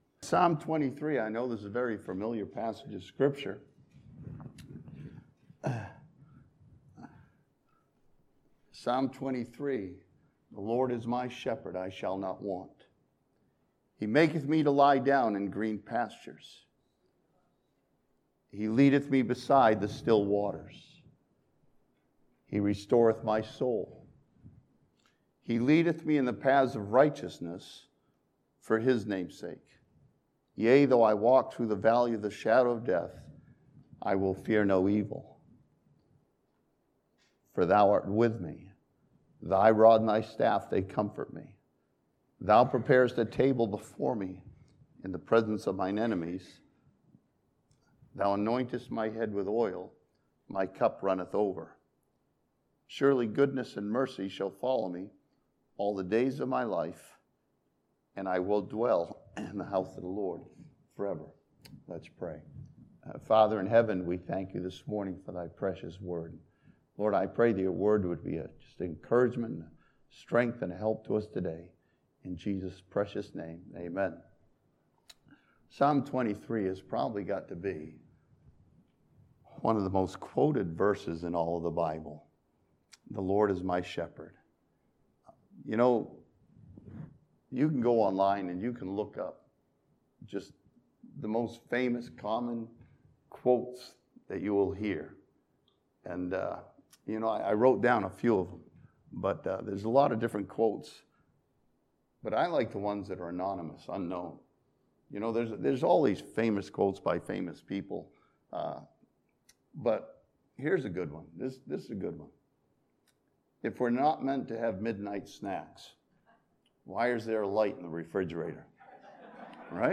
This sermon studies the wonderful Psalm 23 to see the Lord as our wonderful, faithful Shepherd.